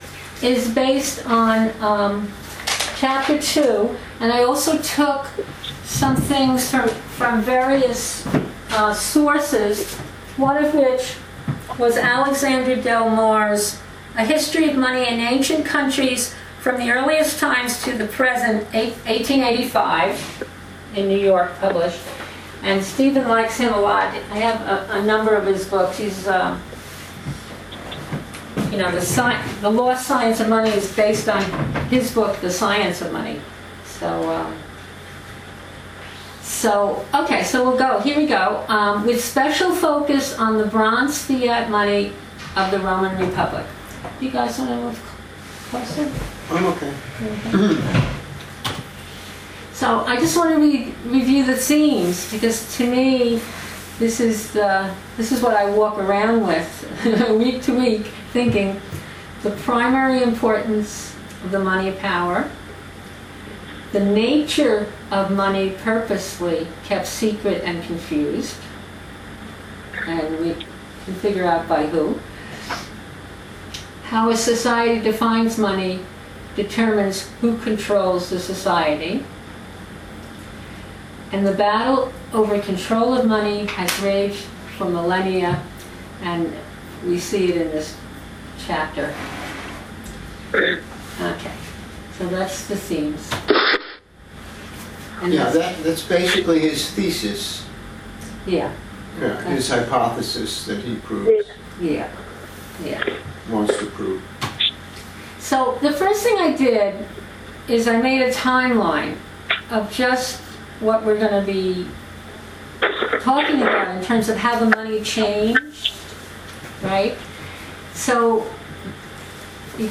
Index of /1/books/Zarlenga, LOST SCIENCE OF MONEY/CHAPTER 02 - ROME'S BRONZE NOMISMA - BETTER THAN GOLD/RECORDING OF CLASS